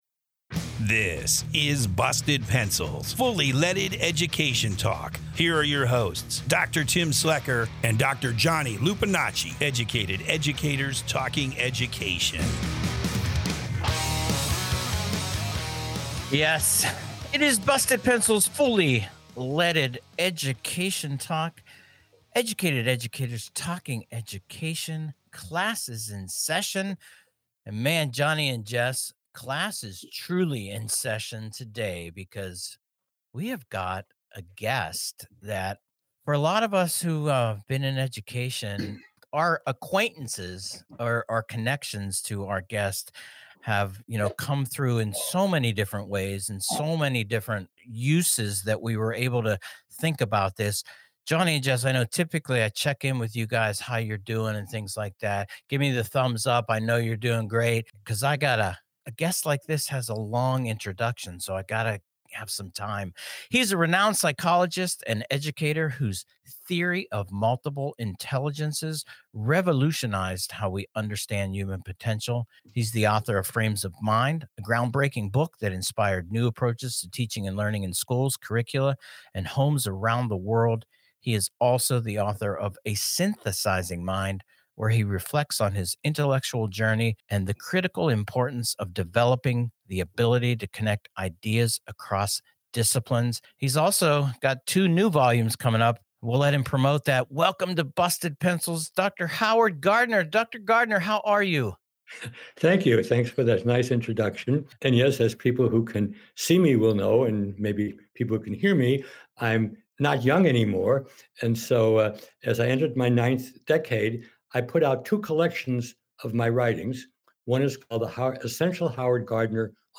Class In Session: Individuation and Pluralization with Dr. Howard Gardner January 15, 2025 Guest: Dr. Howard Gardner Class, this is a truly special session.